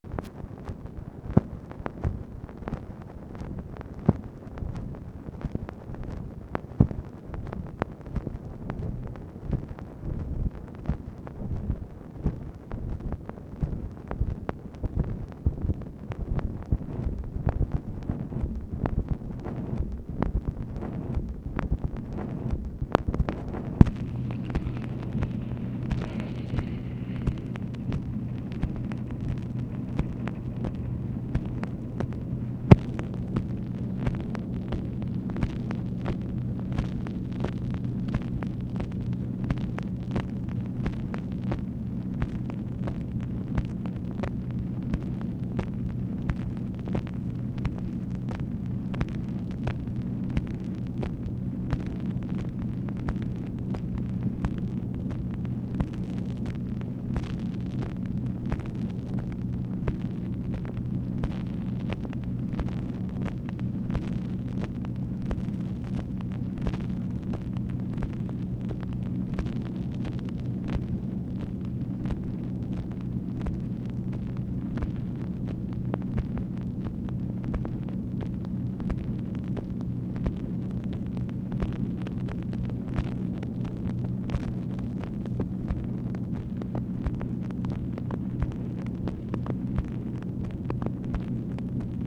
NO AUDIBLE CONVERSATION; POSSIBLY SOMEONE ON HOLD
OFFICE NOISE, January 19, 1966